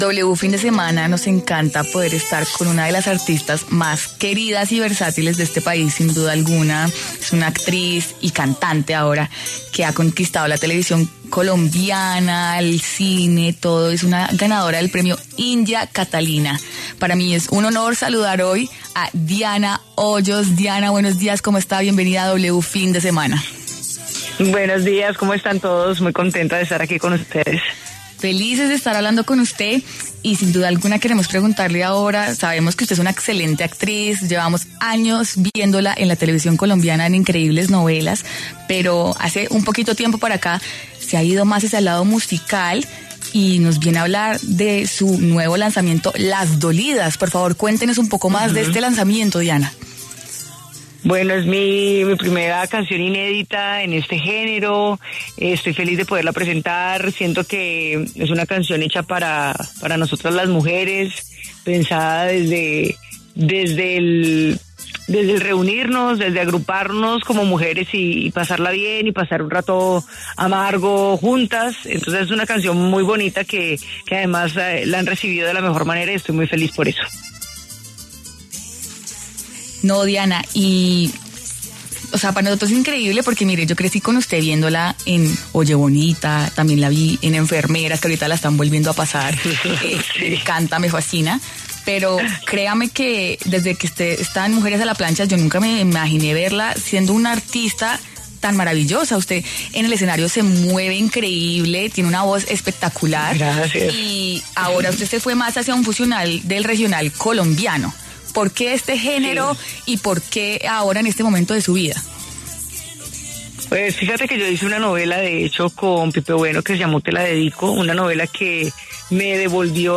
La conocida actriz y cantante colombiana Diana Hoyos habló en W Fin de Semana acerca de sus nuevos proyectos relacionados a la música.